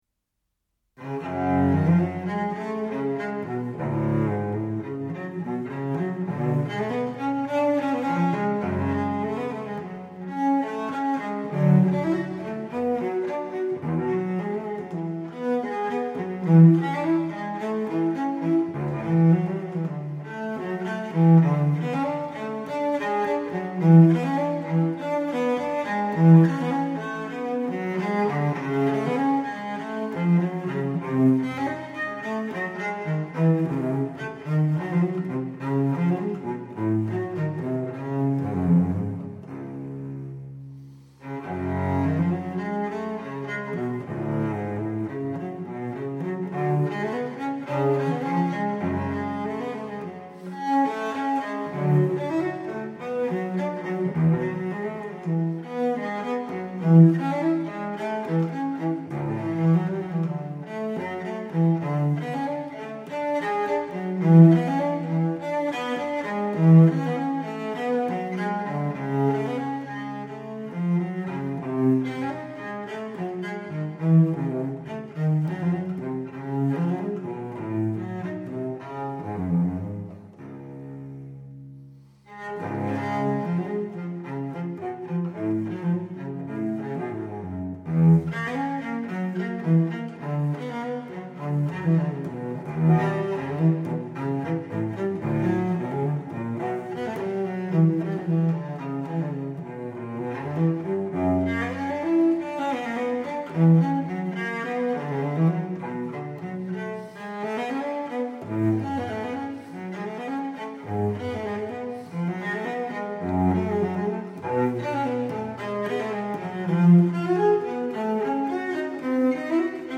Baroque cello
(cello solo)